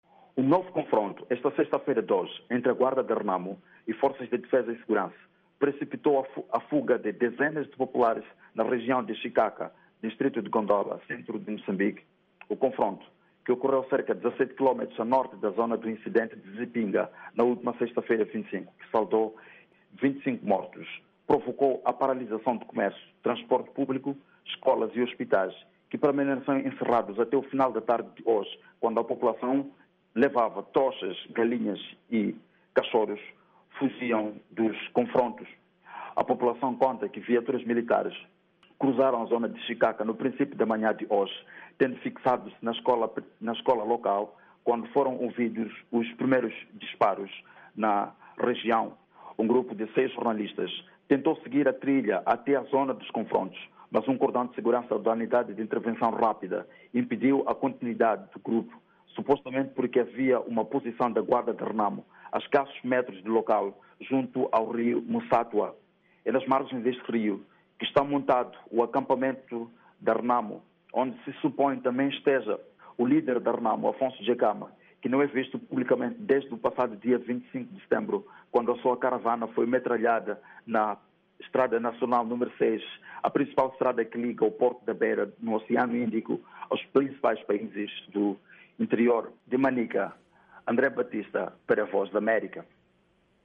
A VOA esteve no local.